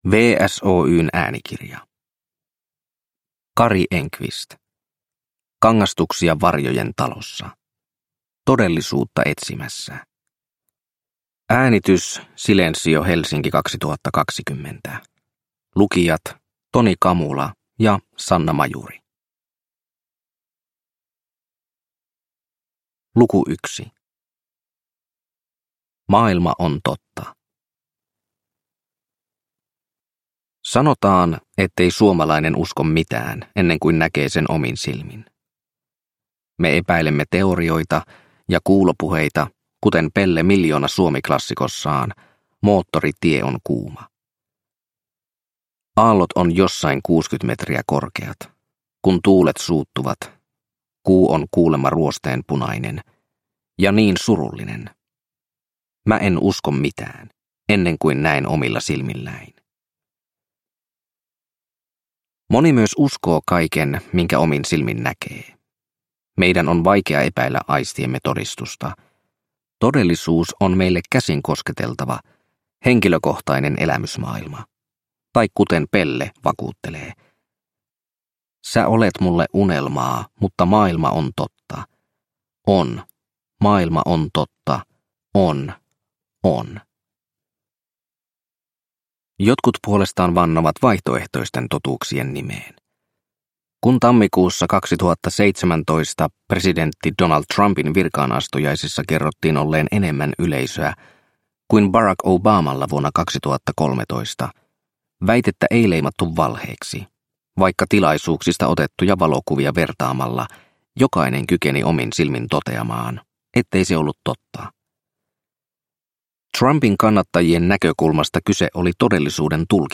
Kangastuksia varjojen talossa – Ljudbok – Laddas ner